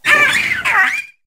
squawkabilly_ambient.ogg